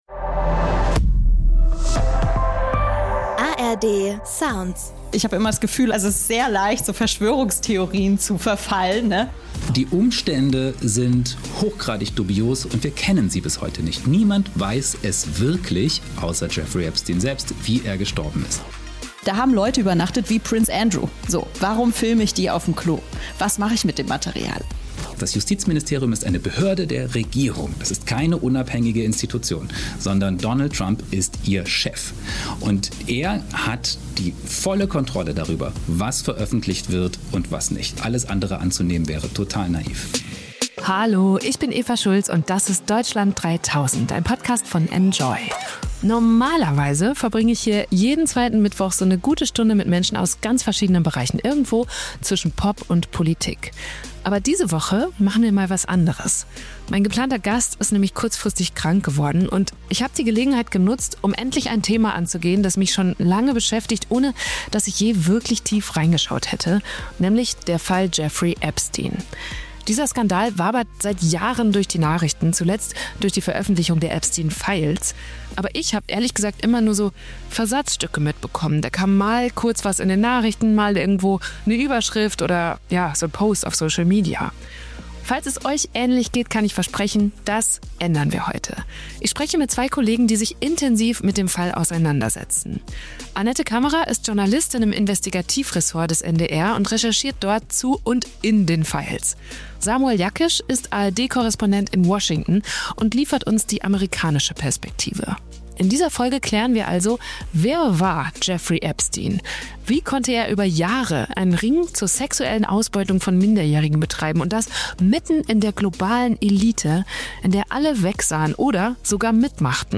Ich spreche mit zwei Kollegen, die sich intensiv mit dem Fall auseinandersetzen.